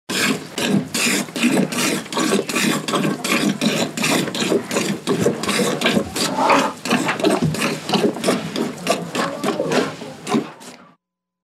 Звуки коровы